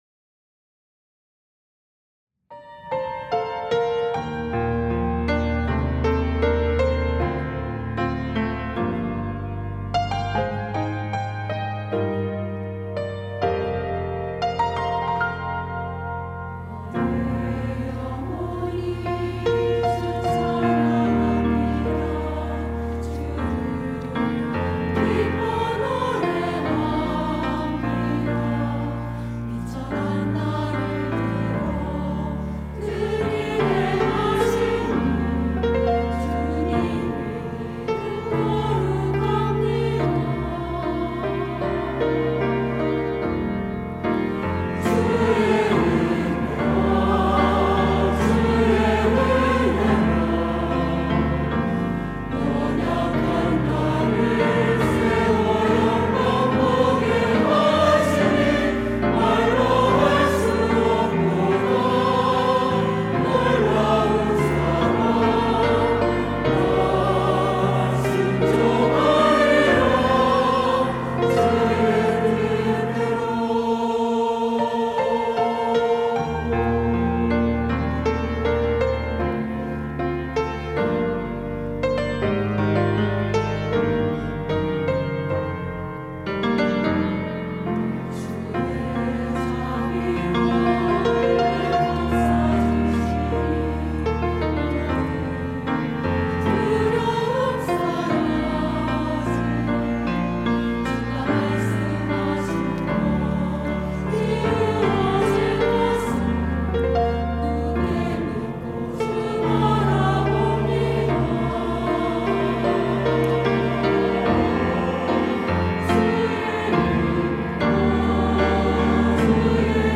할렐루야(주일2부) - 주의 은혜라
찬양대